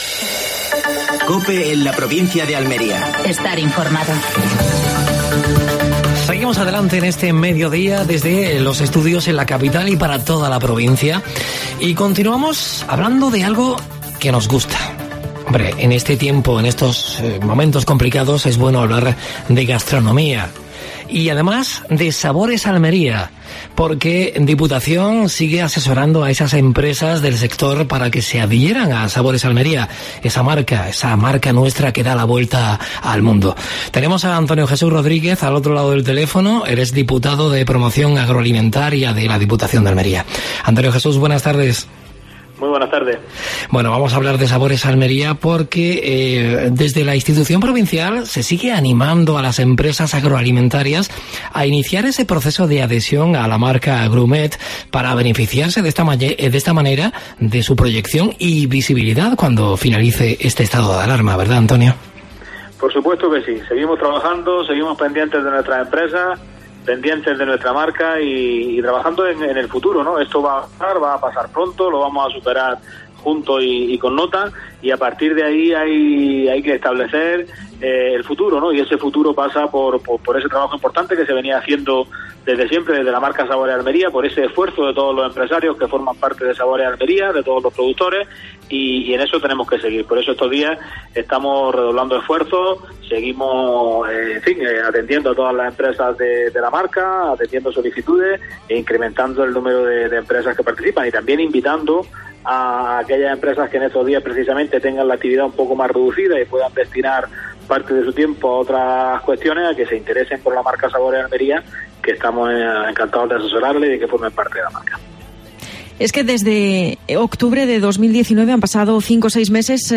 AUDIO: Actualidad en Almería. Entrevista a Antonio Jesús Rodríguez (diputado de Promoción Agroalimentaria de la Diputación Provincial de Almería).